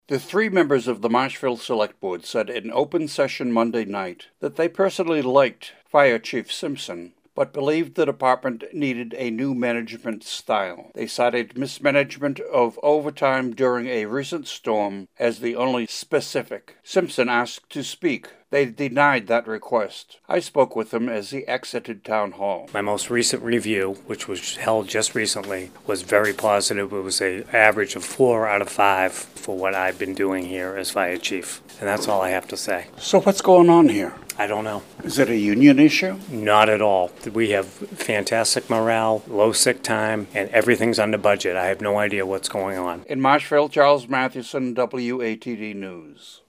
by | Mar 15, 2024 | News